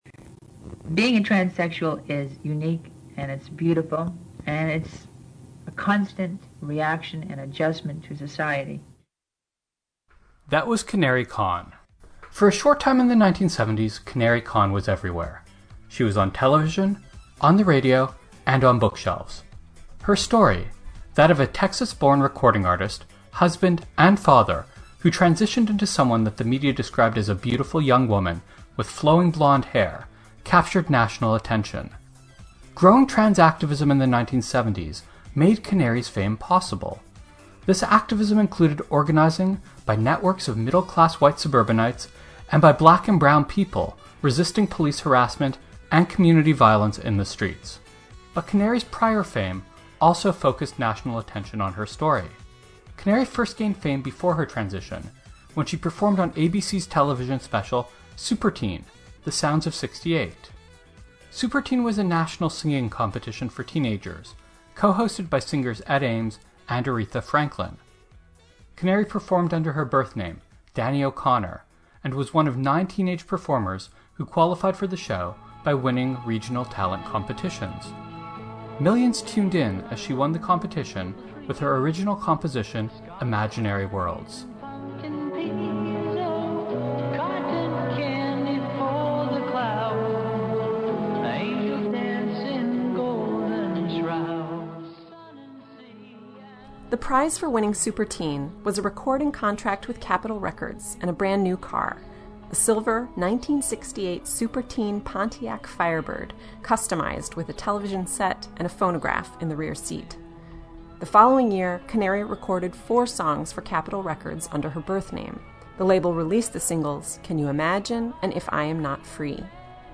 ***** 1977 Interview, produced June 2019 LISTEN